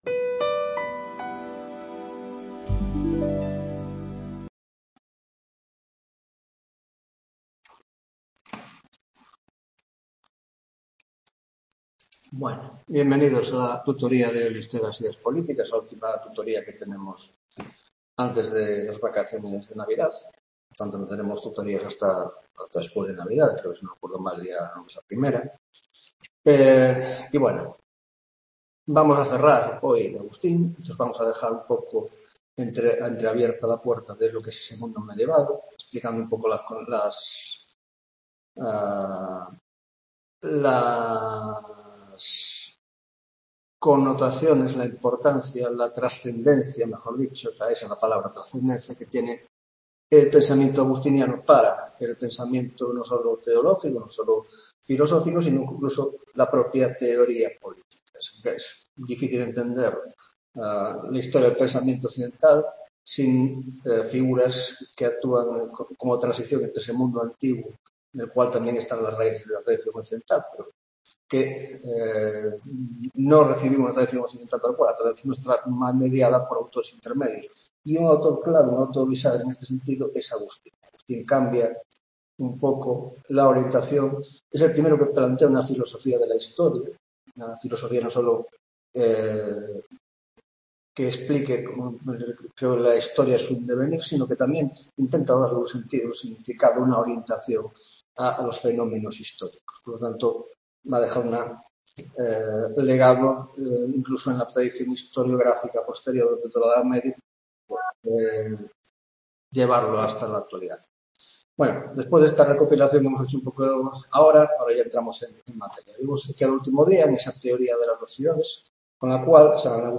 9ª Tutoría de Historia de las Ideas Políticas (Grado de Ciencias Políticas y Grado de Sociología)